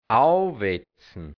Mundart-Wörter | Mundart-Lexikon | hianzisch-deutsch | Redewendungen | Dialekt | Burgenland | Mundart-Suche: A Seite: 17